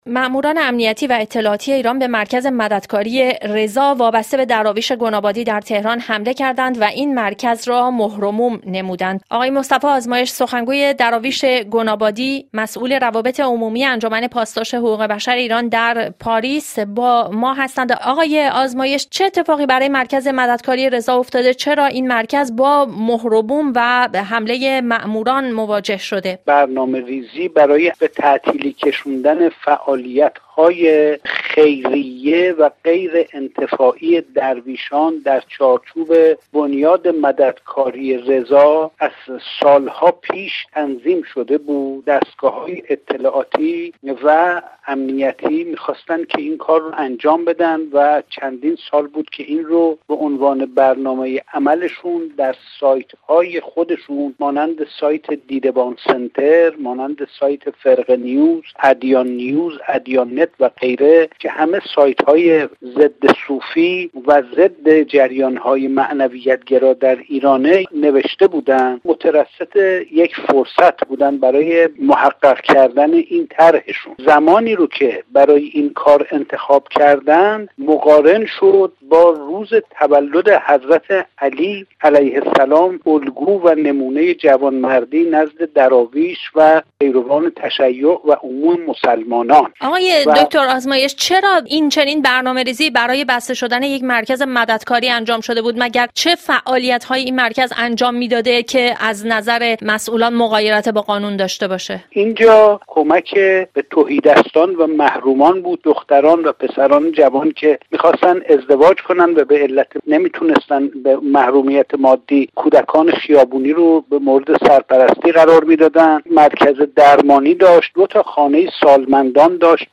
گفت‌و‌گو